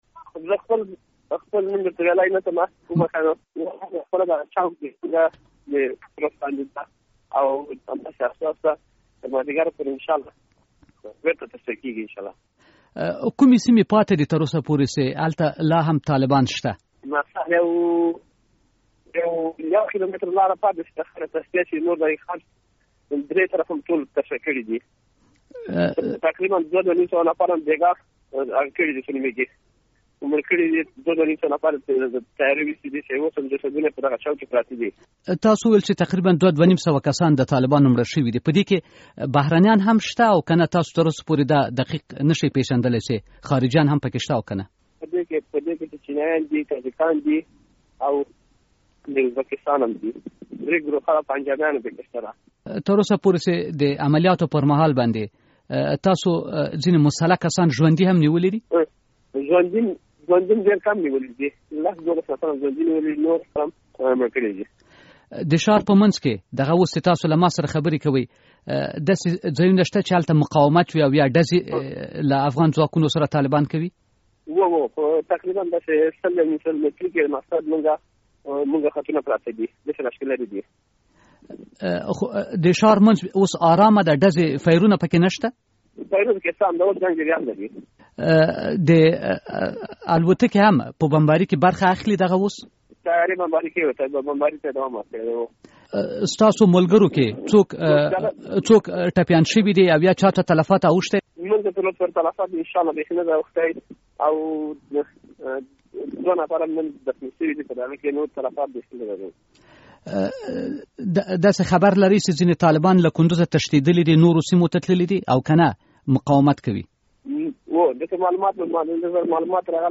د کندز د ښار په مرکزي چوک کې له یوه افغان سرتیري سره مرکه